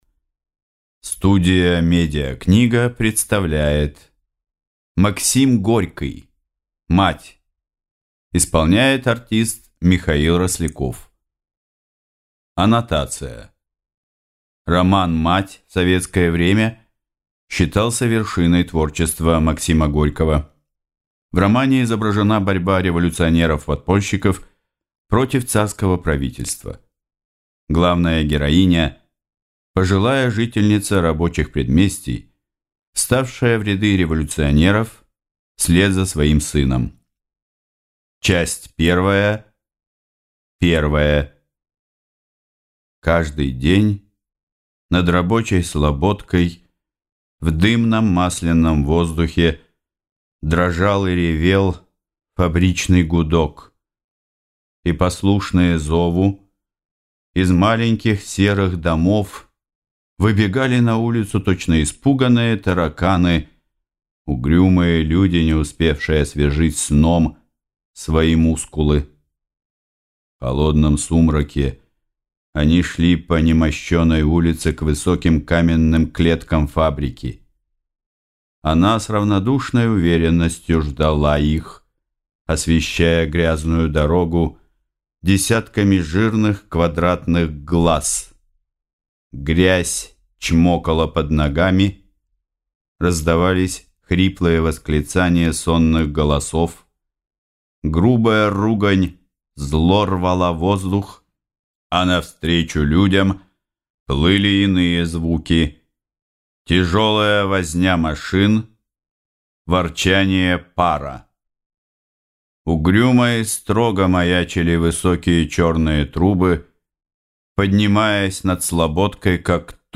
Аудиокнига Мать - купить, скачать и слушать онлайн | КнигоПоиск
Аудиокнига «Мать» в интернет-магазине КнигоПоиск ✅ Классика в аудиоформате ✅ Скачать Мать в mp3 или слушать онлайн